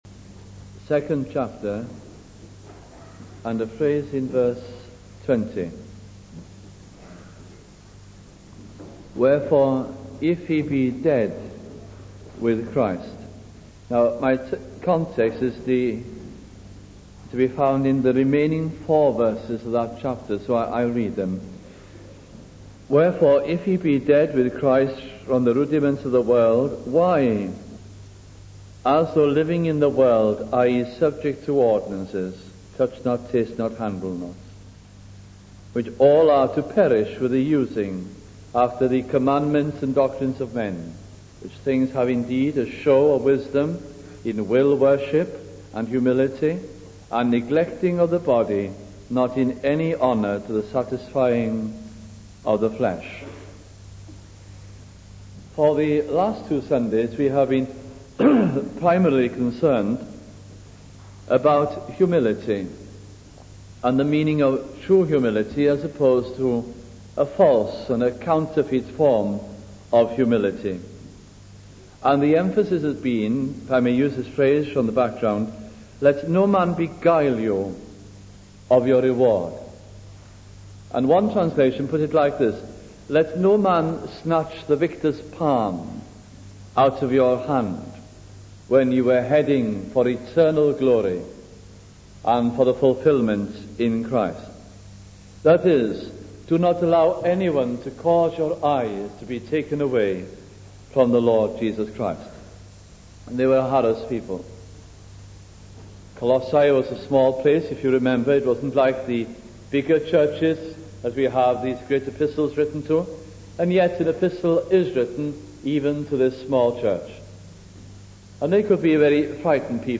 » Colossians Series 1973 » Please note that due to missing parts of the historic audit of recordings this series is incomplete » We also regret that a few sermons in this series do not meet the Trust's expectations of the best sound quality.